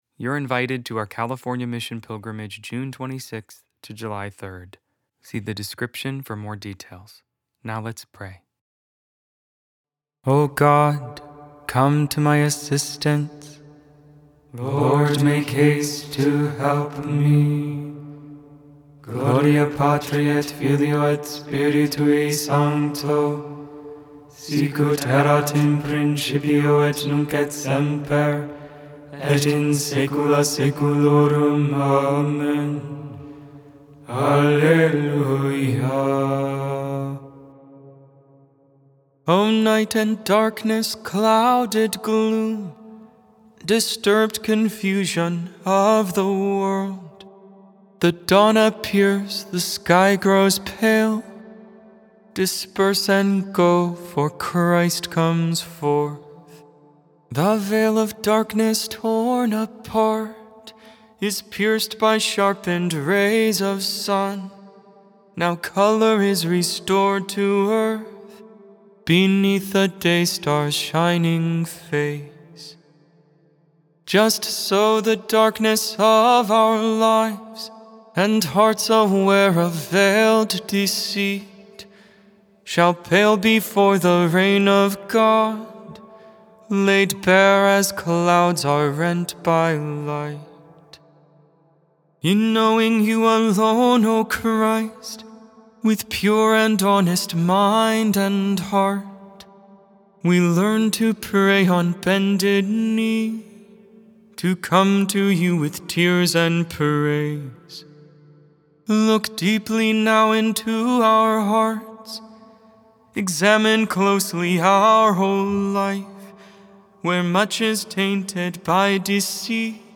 Lauds, Morning Prayer for the 5th Wednesday in Ordinary Time, February 11, 2026.Made without AI. 100% human vocals, 100% real prayer.